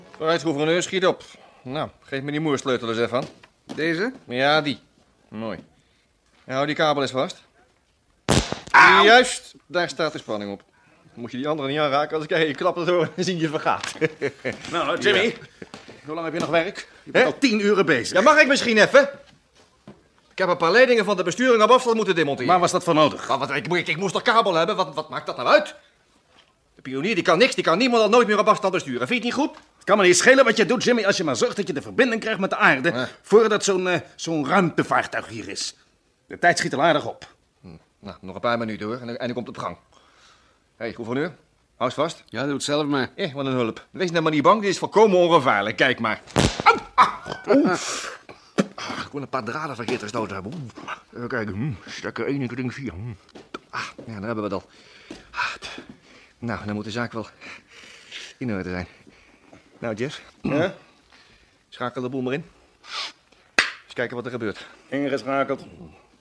Nu schijnen Britten over het algemeen erg beleefd te zijn, maar voor mij wederom een duidelijk verschil in acteren (of was het ook de regie?).